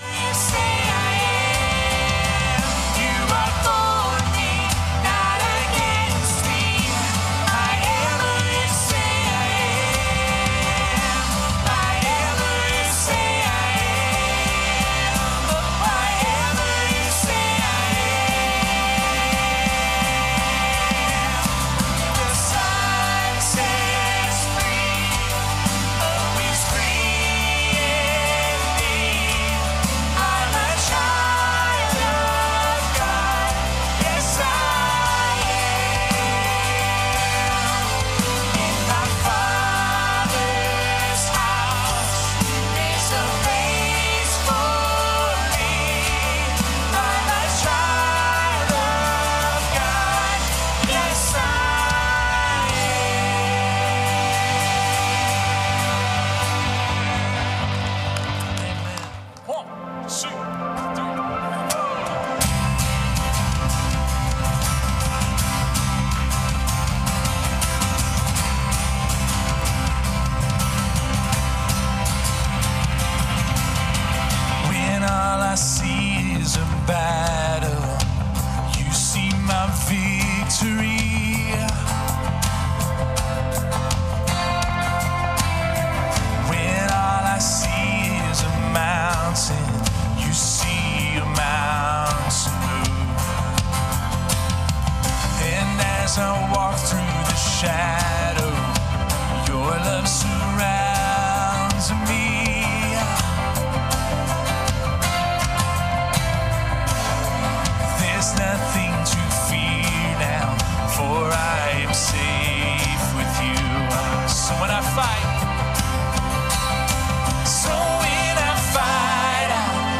MP3 Notes Sermons in this Series Loving Like Jesus Reframing Baptism Be Not Afraid…